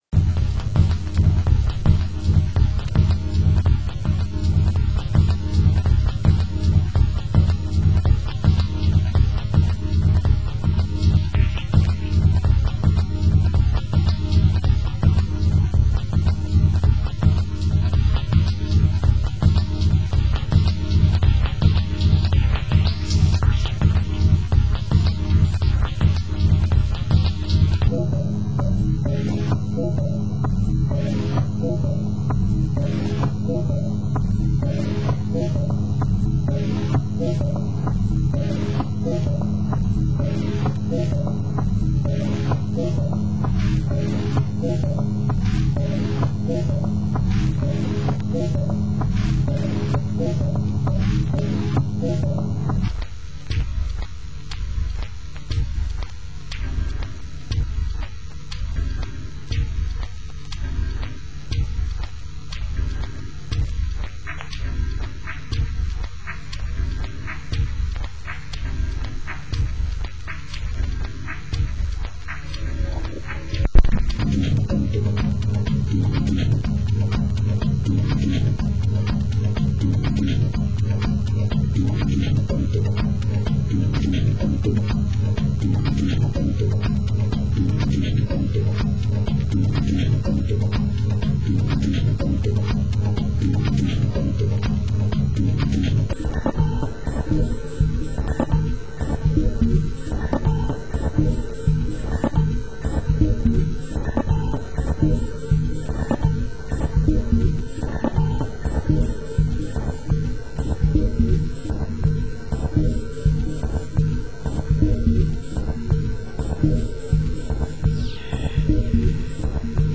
Genre: Techno
Genre: Experimental